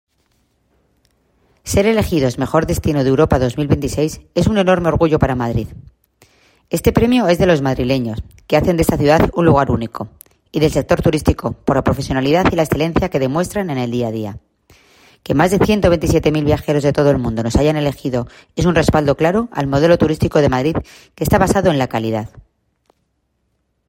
La concejala delegada de Turismo, Almudena Maíllo: